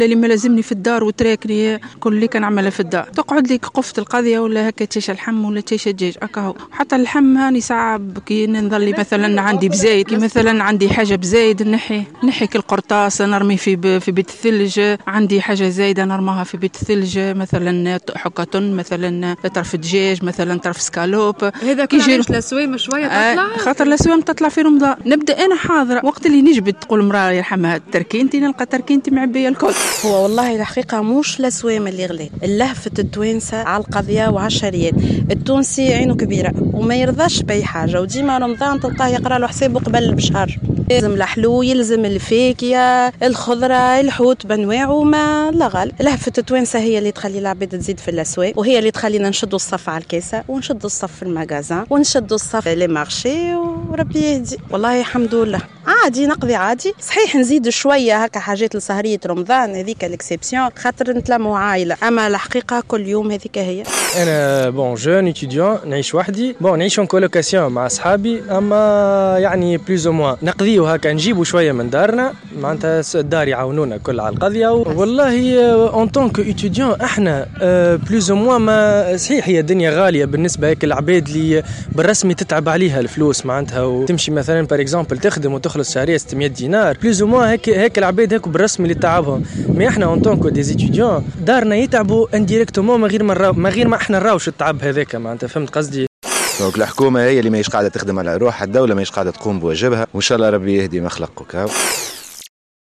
عبّر عدد من التونسيين عن تذمّرهم من ارتفاع أسعار المواد الاستهلاكية، أياما قبل شهر رمضان.